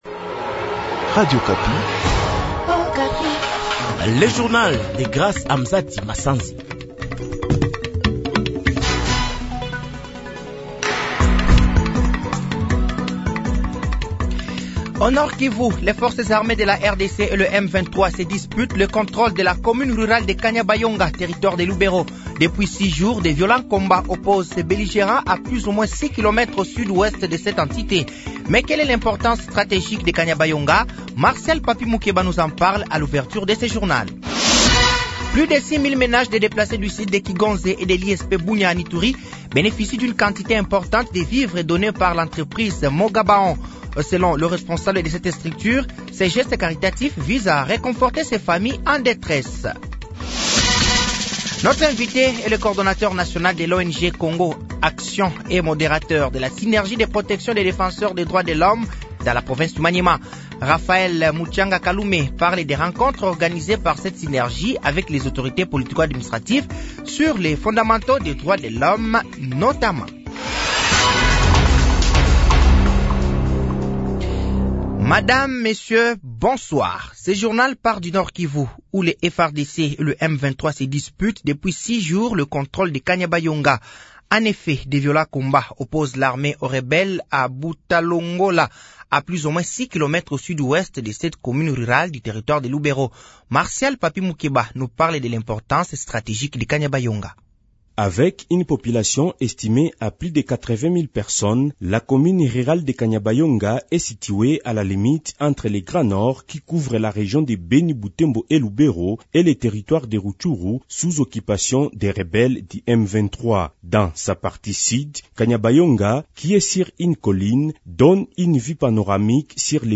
Journal français de 18h de ce samedi 01 juin 2024